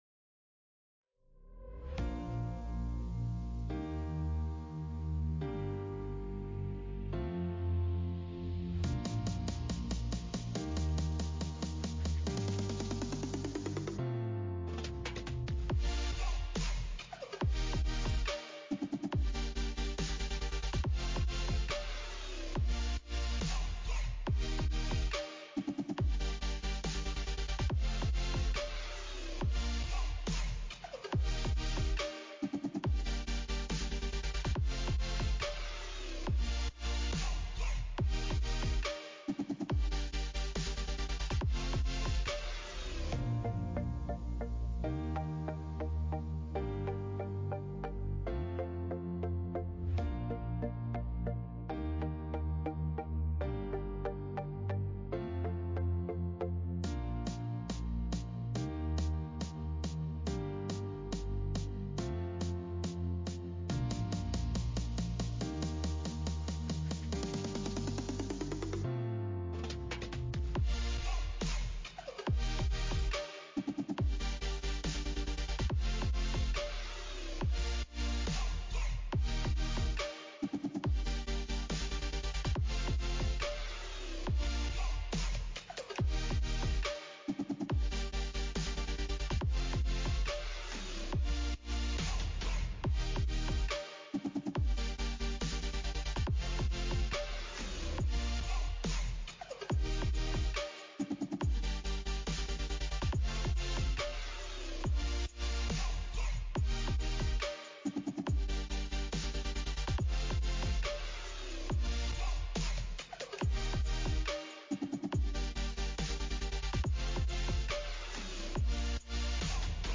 Responsibility-Calling-Sermon-Audio-CD.mp3